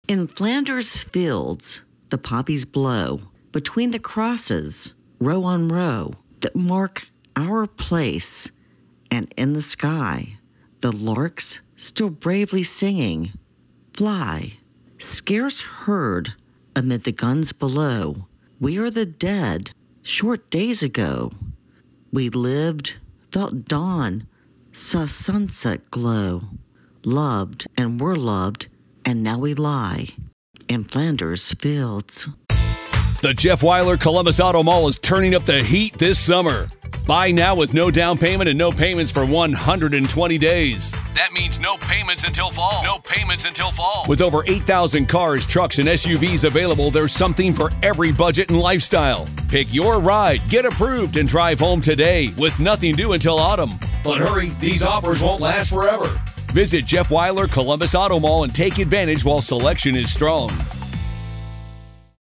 When the poem, Flander's Field by John McCrae, written about a battle during World War 1 is read at Memorial Day ceremonies, most people miss the second sentence because the reader normally gets choked up after the first sentence. Here is a reading of the poem